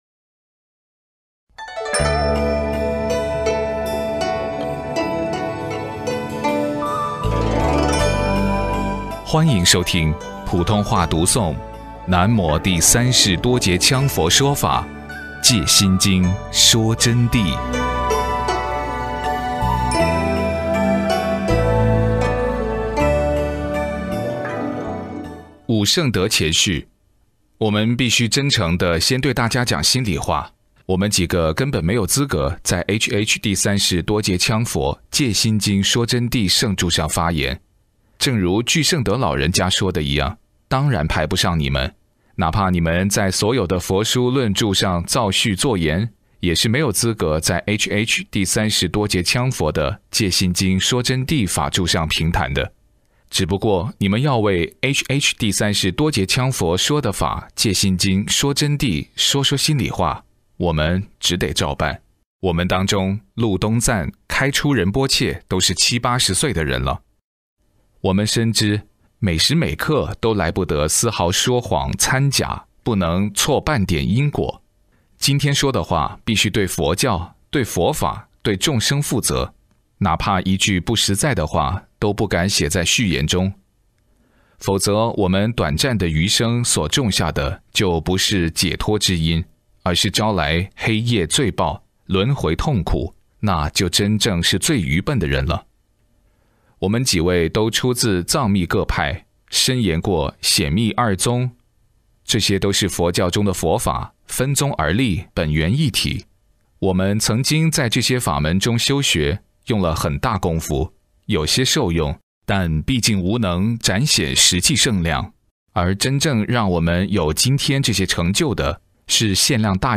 002 普通話恭誦–南無第三世多杰羌佛說法《藉心經說真諦》前言8-10頁